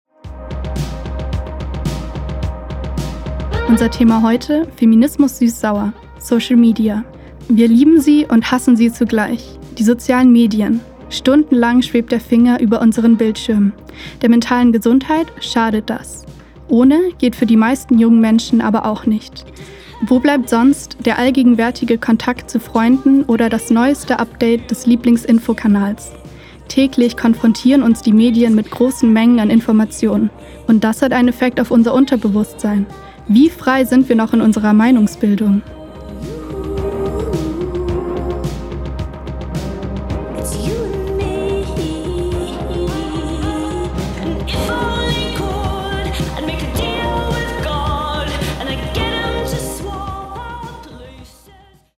Moderation: